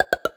Special Pop (7).wav